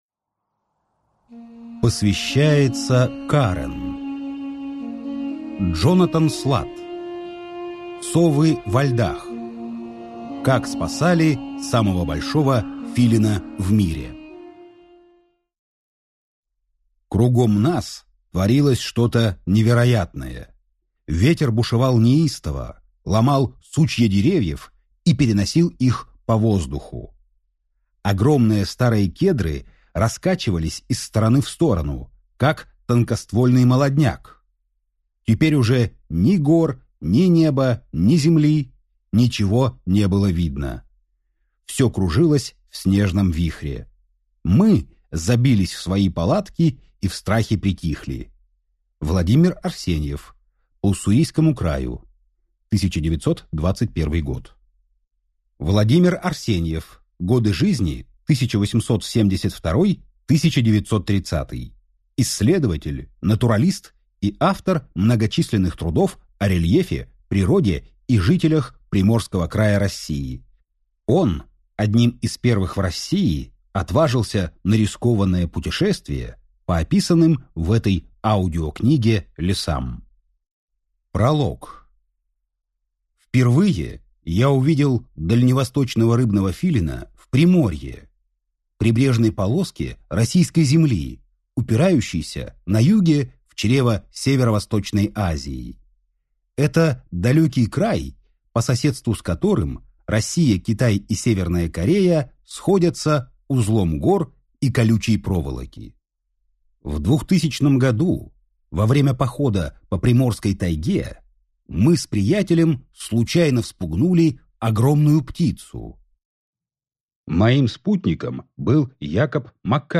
Аудиокнига Совы во льдах: Как спасали самого большого филина в мире | Библиотека аудиокниг